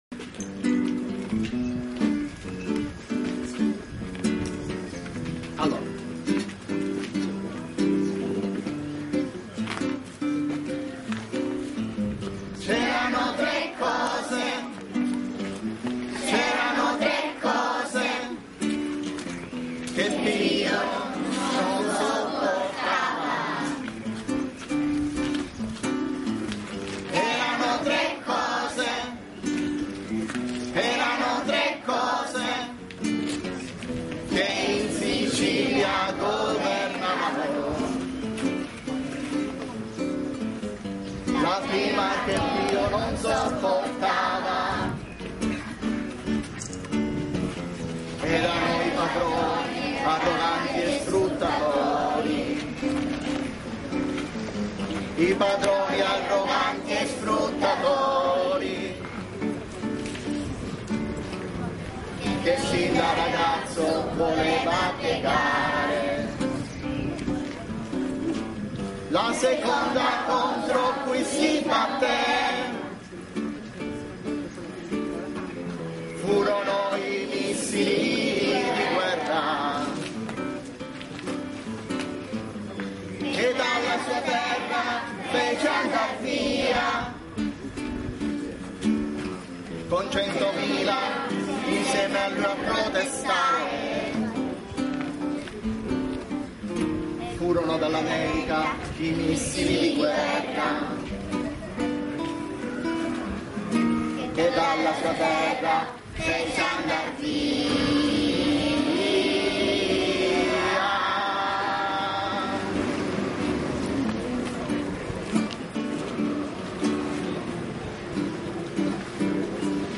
30 aprile 1982 voce e chitarra